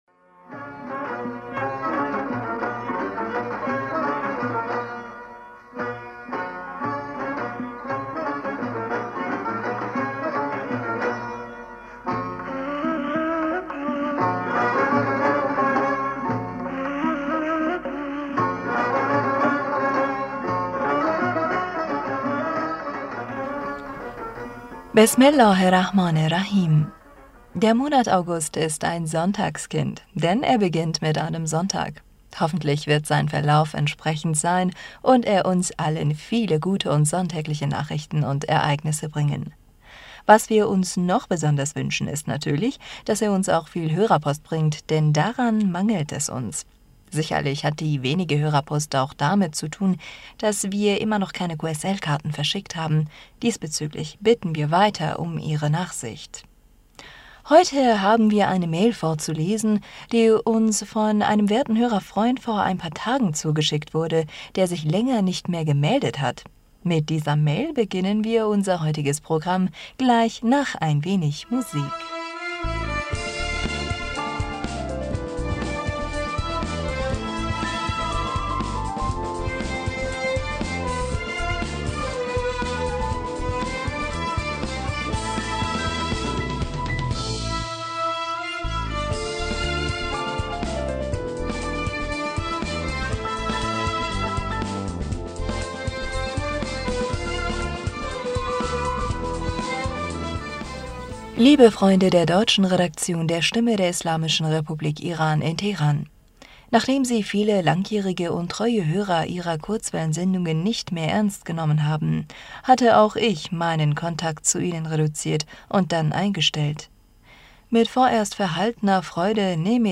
Heute haben wir eine Mail vorzulesen, die uns von einem werten Hörerfreund vor ein paar Tagen zugeschickt wurde, der sich länger nicht mehr gemeldet hat. Mit dieser Mail beginnen wir unser heutiges Programm, gleich nach ein wenig Musik.